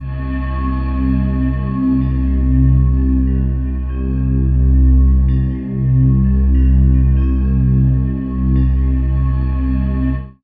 36ak01pad1cM.wav